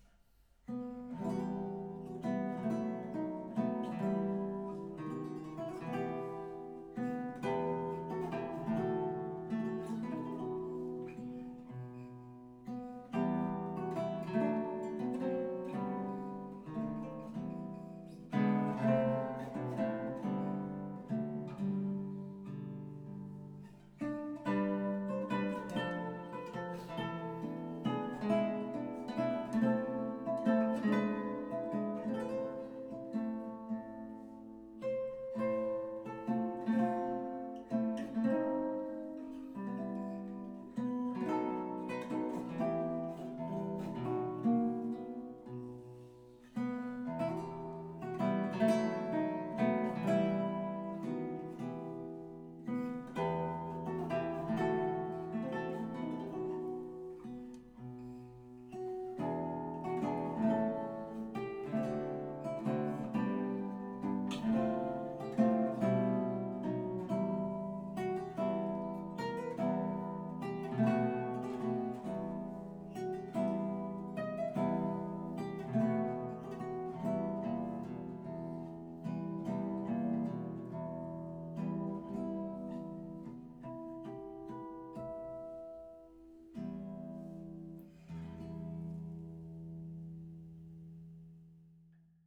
Un suono dolce e ricco di armonici dove l'equilibrio delle parti resta sempre evidente principalmente in situazioni accordali ed armonicamente dense.